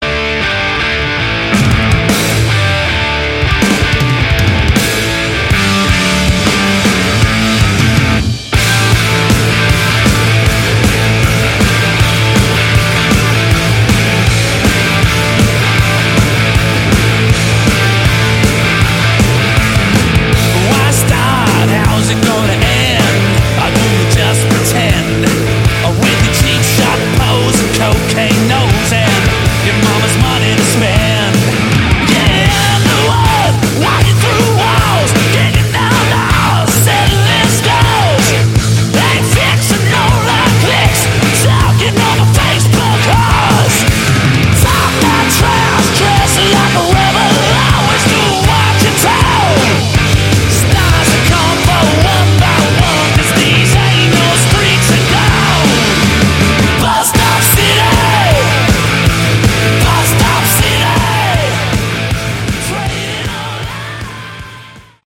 Category: Hard Rock
vocals, bass
guitars
drums